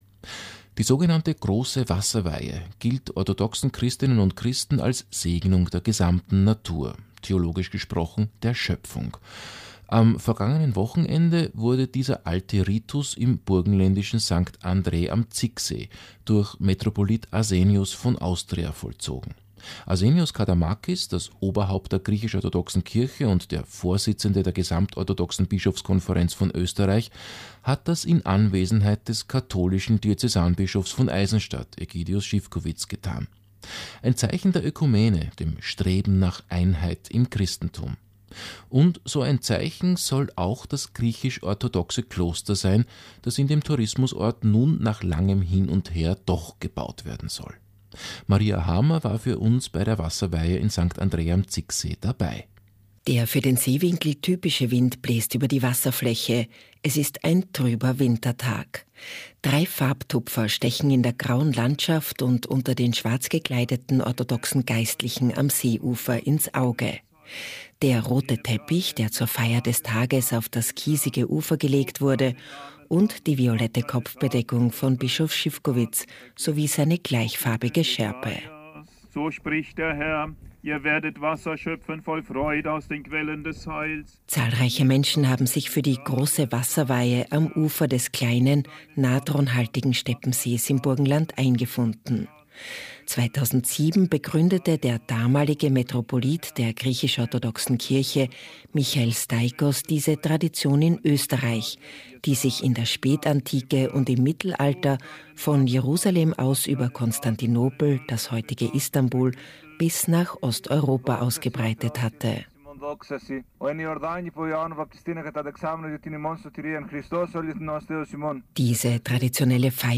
Es enthält einen Ö1-Radiobeitrag vom 04.02.2018. Sie können es unter diesem Link abrufen. Ö1 „Lebenskunst“: Die Schöpfung segnen – Die orthodoxe „Große Wasserweihe“